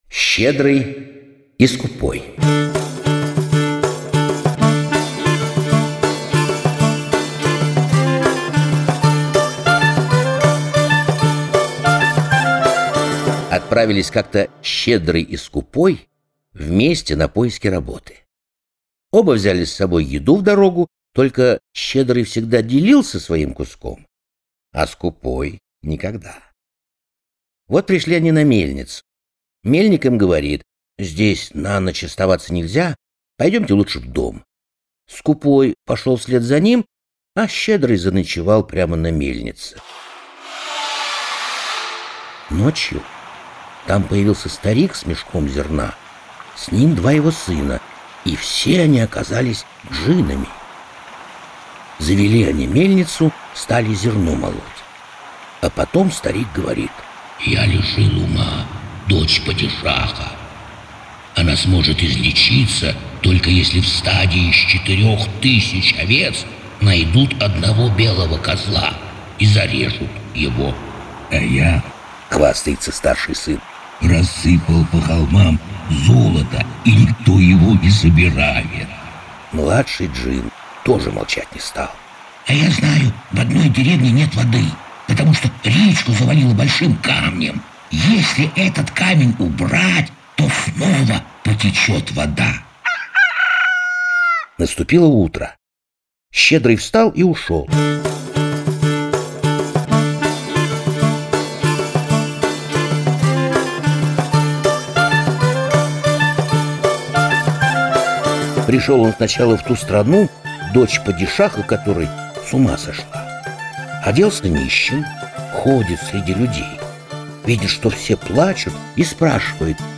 Щедрый и Скупой - крымскотатарская аудиосказка - слушать онлайн